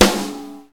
soft-hitclap2.ogg